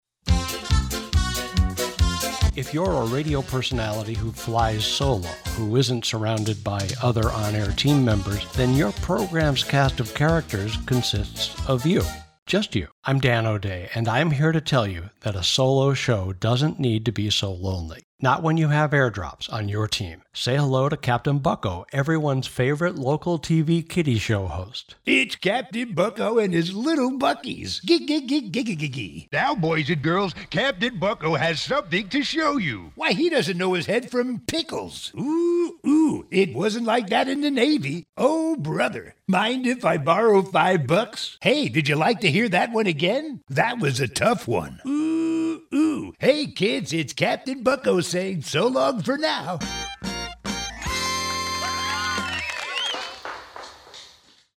They needed character voices for what they called Air-Drops for radio station Dj's. Air-Drops are little voice-liners about 5-10 sec's in length, usually comedic in tone and designed to help Dj's segue between programs or sequence shifts.
They liked a voice I did that was inspired by the original Burger King voice of yore.
I always liked BK's original animated Where Kids are King Voice. I just gave it some 'flare'.